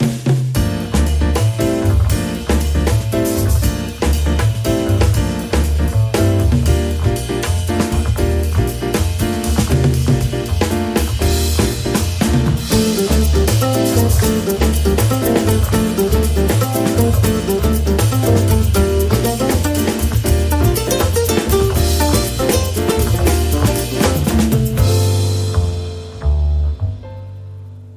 • Качество: 112, Stereo